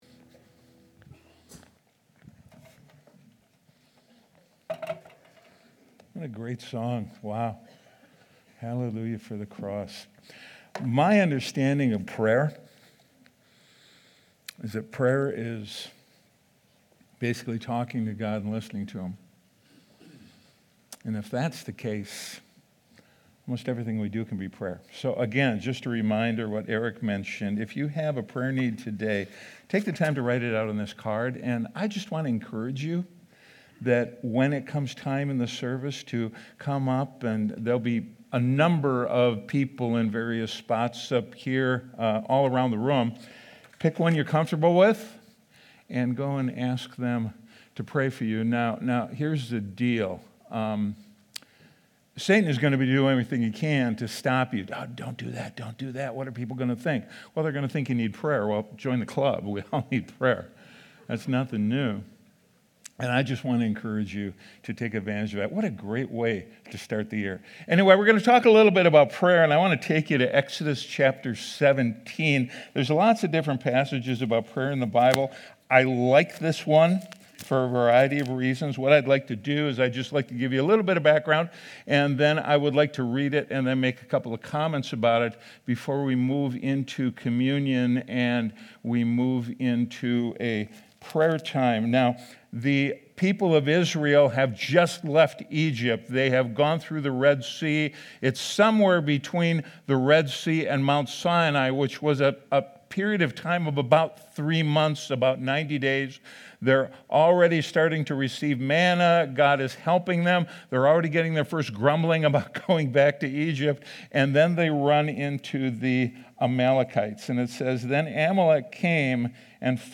Sunday Sermon: 1-11-26